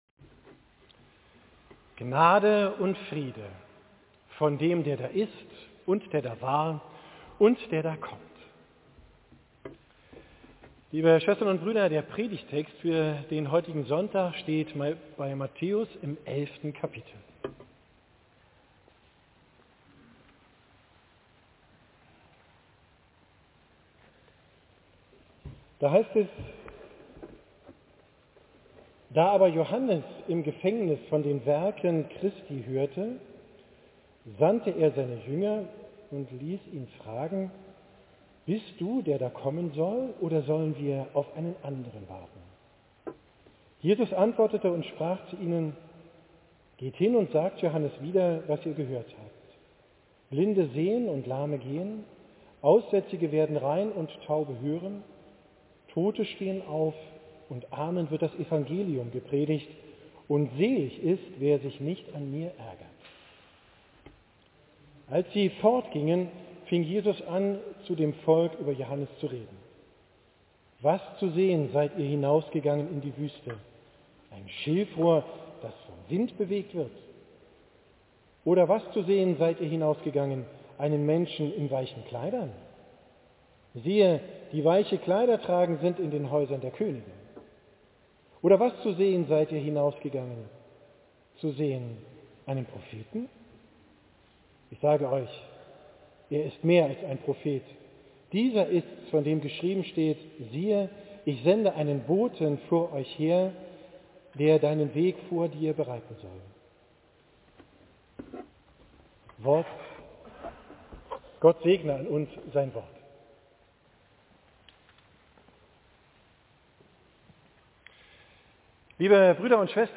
Predigt vom 3. Sonntag im Advent, 17.